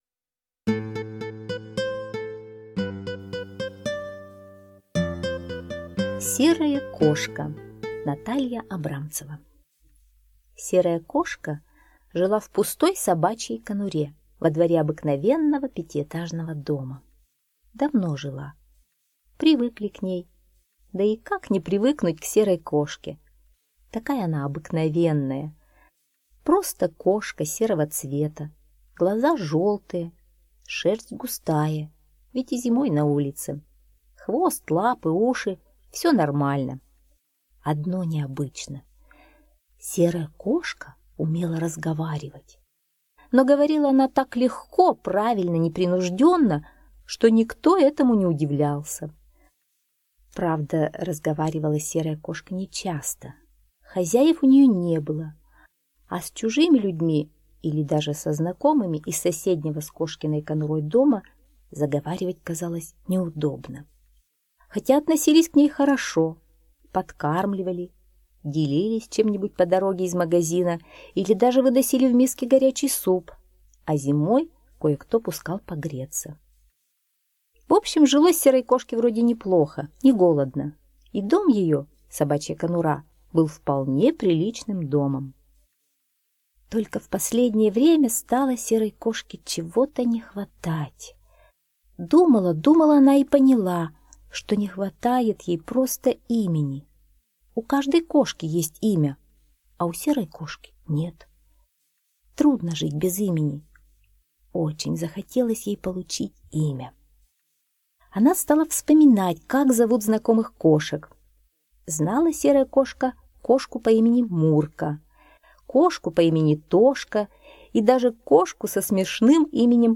Слушайте Серая кошка - аудиосказка Абрамцевой Н. Сказка про обычную серую кошку, которая жила на улице в пустой собачьей конуре.